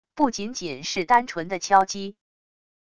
不仅仅是单纯的敲击wav音频